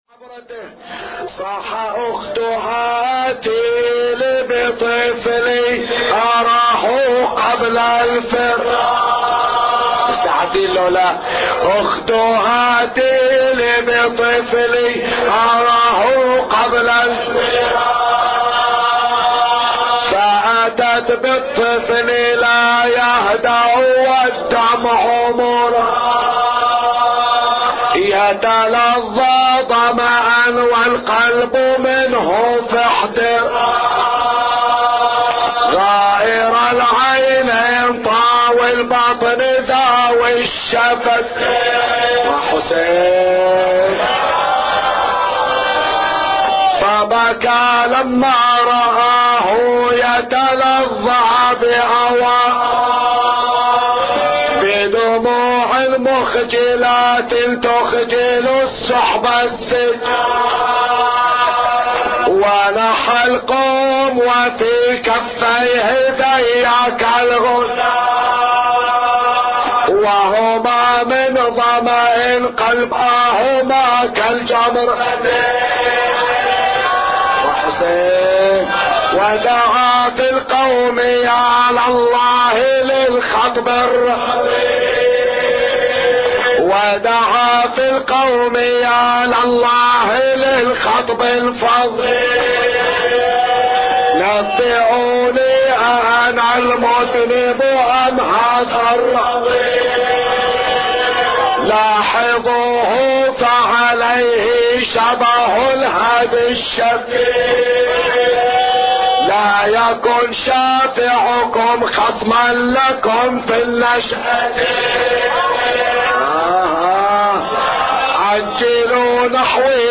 أبيات حسينية – ليلة العاشر من شهر محرم